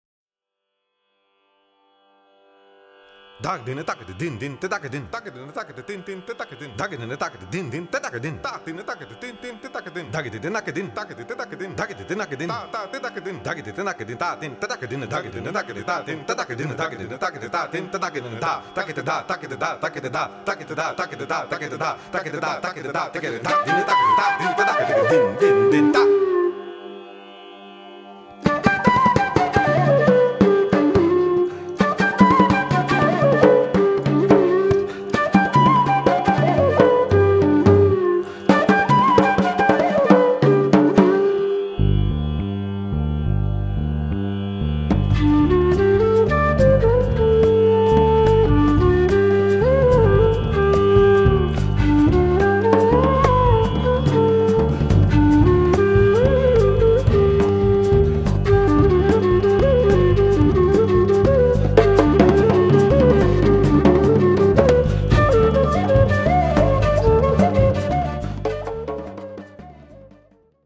Genre: World Fusion.
bansuri and voice percussion
electric bass
drums and bongos